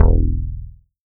UK BASS 1.wav